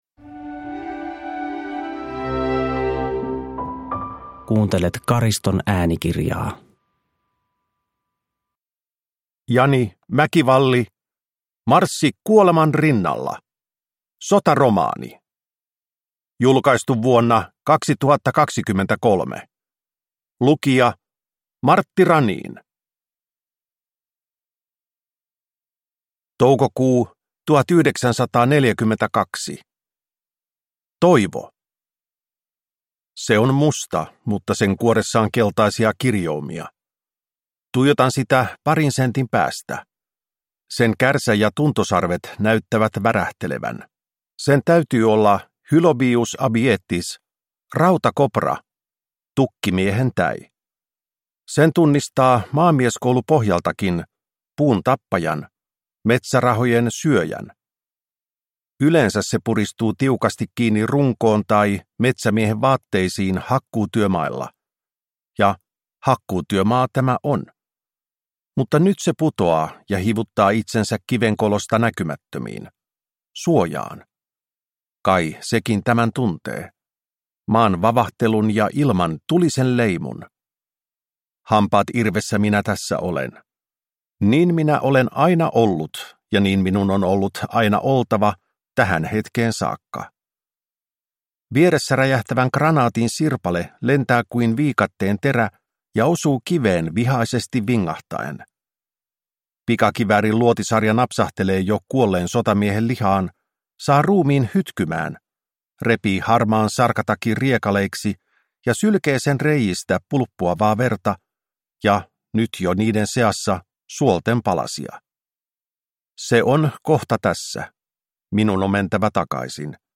Marssi kuoleman rinnalla – Ljudbok – Laddas ner